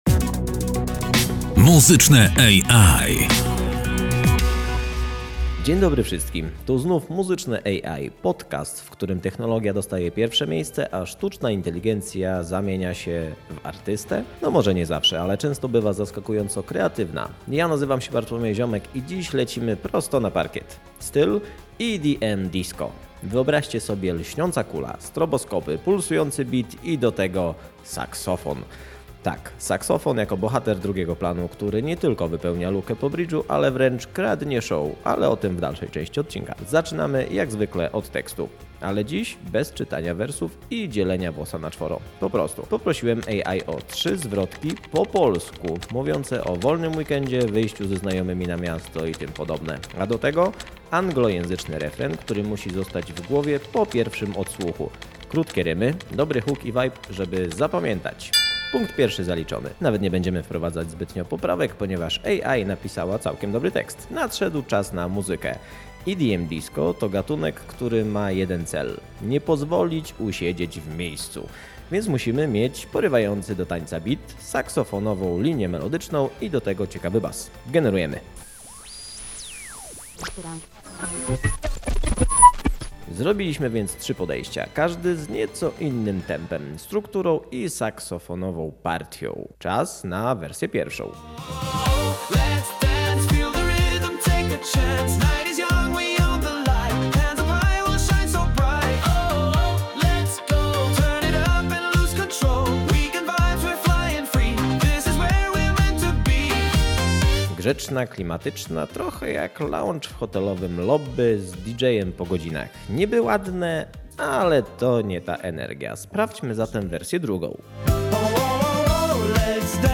Zabieramy Was w podróż do świata EDM Disco – tam, gdzie pulsujący beat spotyka błysk stroboskopów, a saksofon staje się nieoczekiwanym bohaterem.
AI dostała zadanie: napisać tekst o wolnym weekendzie i wypadzie na miasto – po polsku, z angielskim refrenem, który wpada w ucho. Potem przyszedł czas na muzykę: trzy podejścia, trzy różne energie, ale tylko jedna wersja zyskała zielone światło – z wyrazistym basem, przestrzennym refrenem i saksofonem, który kradnie show.
To próba stworzenia tanecznego hitu z pomocą sztucznej inteligencji – bez intro, bez zbędnych ozdobników, za to z sercem bijącym w rytmie disco.